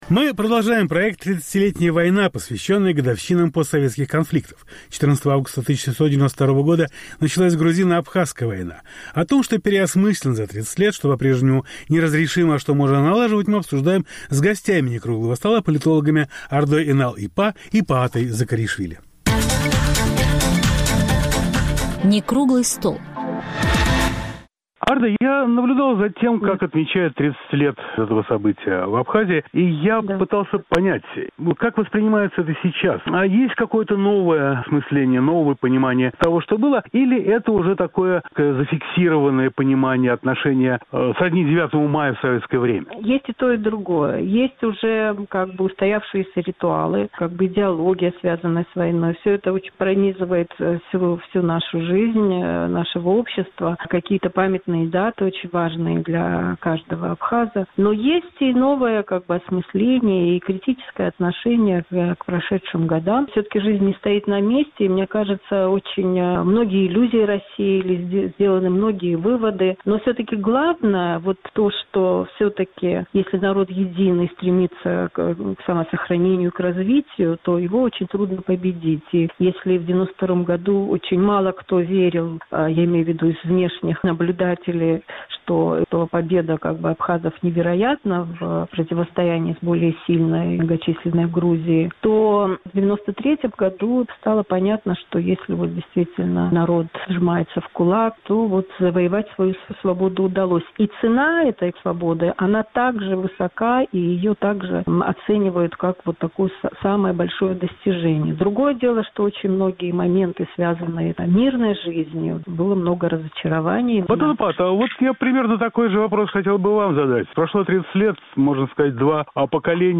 О том, что переосмыслено за тридцать лет, что по-прежнему неразрешимо и что можно начать налаживать, мы обсуждаем с гостями Некруглого стола...